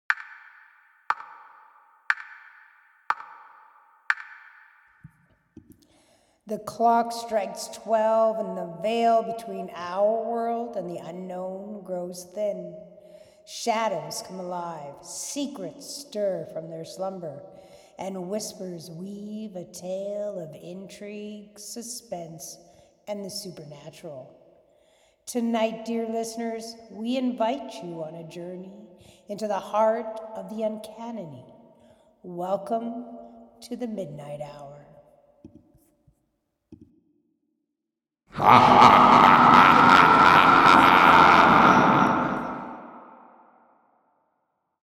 Recording 1: First voice recording and addition of two sound bites (clicking clock, and scary laugh)
HOST (with a smooth, foreboding tone):